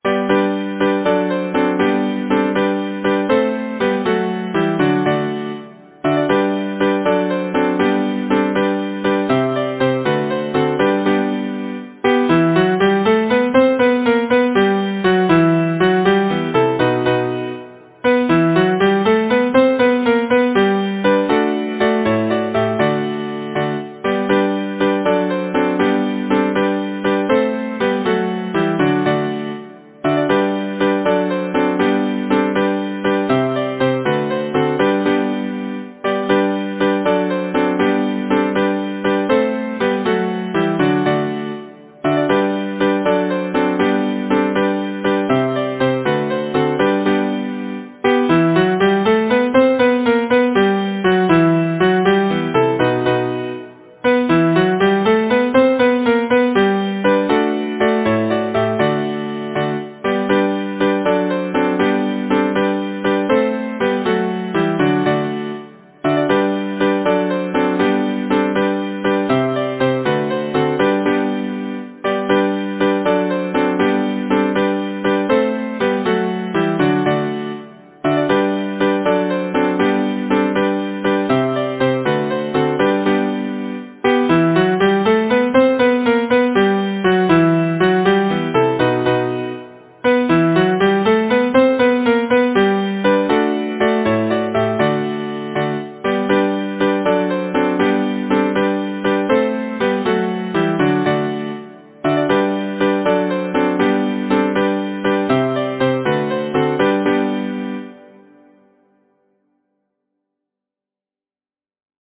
Title: Song of the brook Composer: Edwin T. Pound Lyricist: Number of voices: 4vv Voicing: SATB Genre: Secular, Partsong
Language: English Instruments: A cappella